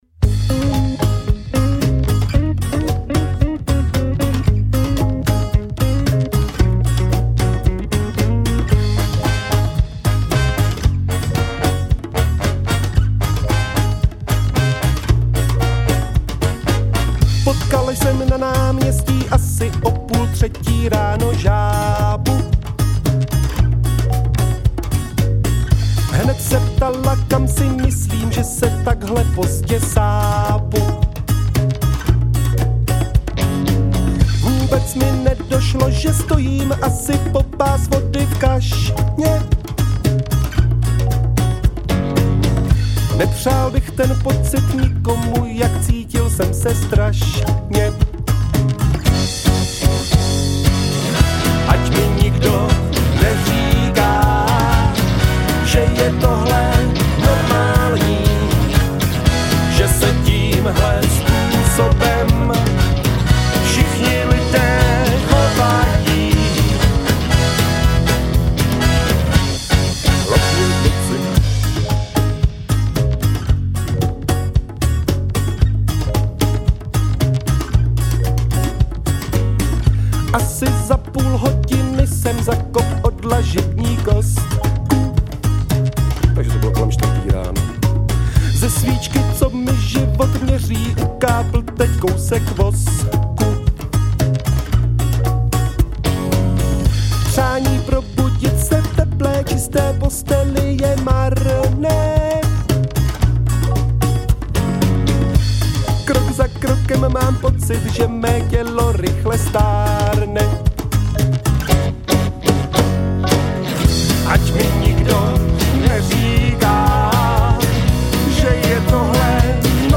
Žánr: Indie/Alternativa